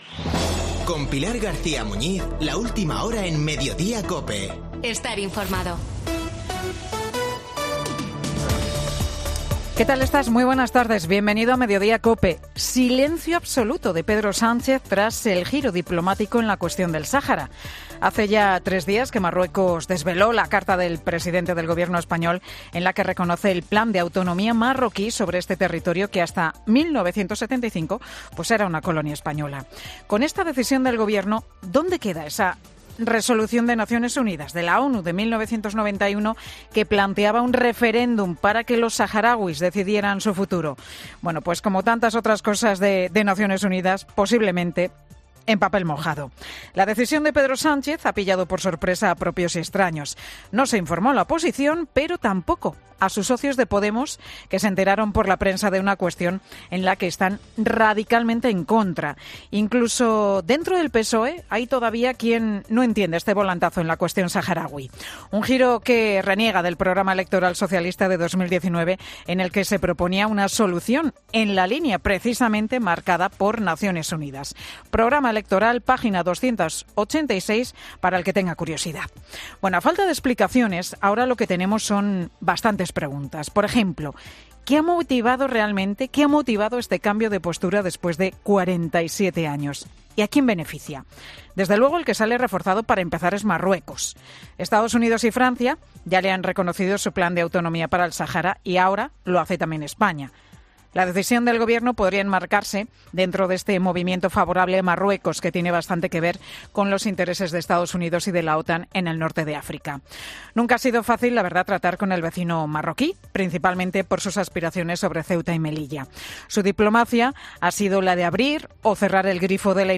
El monólogo de Pilar García Muñiz, en Mediodía COPE